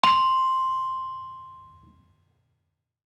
Saron-5-C5-f.wav